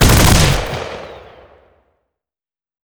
fire2.wav